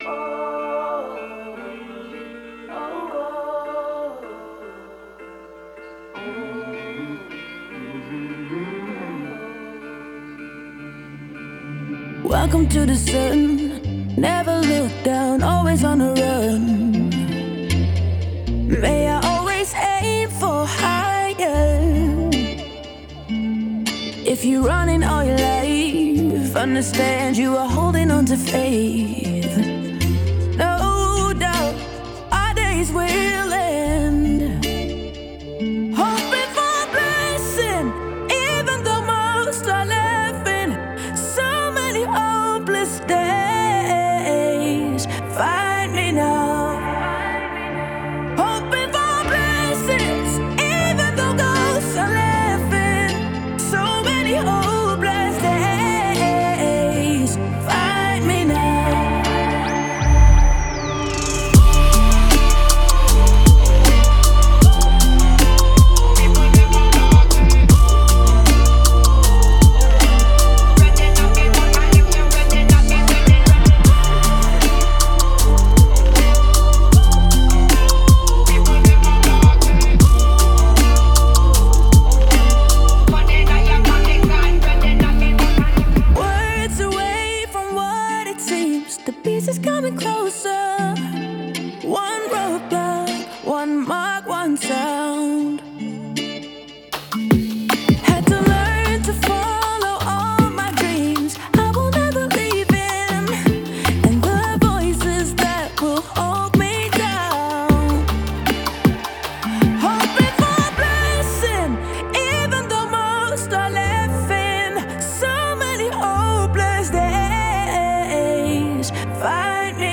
энергичная поп- и EDM-композиция